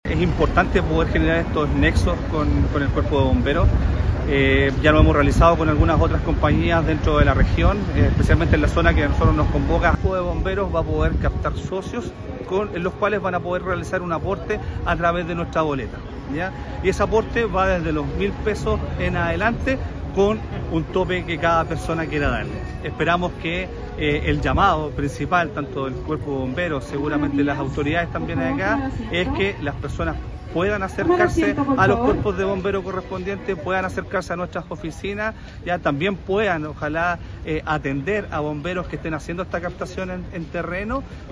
NOTA DE AUDIO de "Esval y Bomberos de Quillota sellan importante acuerdo: voluntarios recibirán aportes a través de la boleta de agua"